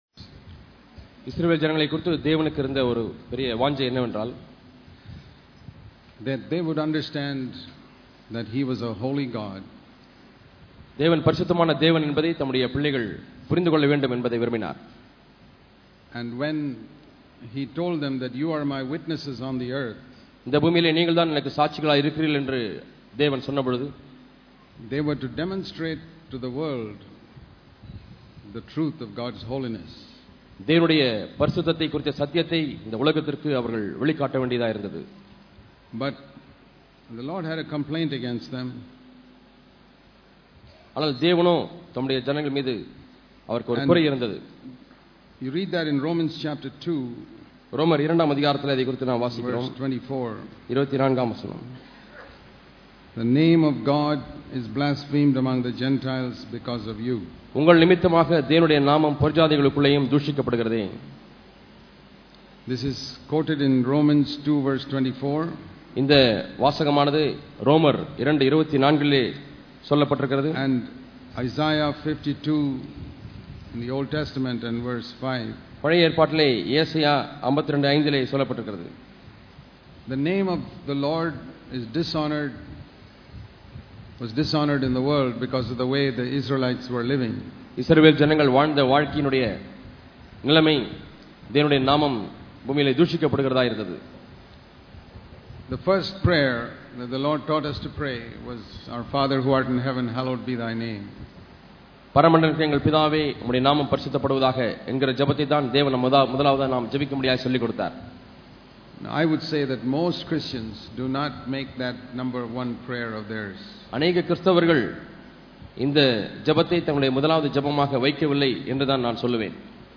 Holiness and Fellowship Click here to View All Sermons இத்தொடரின் செய்திகள் தேவன் எல்லாவற்றையும் நன்றாக அறிந்துள்ளார் என்ற உணர்வை பெற்றுள்ளேனா?